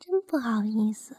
害羞2.wav
害羞2.wav 0:00.00 0:01.19 害羞2.wav WAV · 102 KB · 單聲道 (1ch) 下载文件 本站所有音效均采用 CC0 授权 ，可免费用于商业与个人项目，无需署名。
人声采集素材/人物休闲/害羞2.wav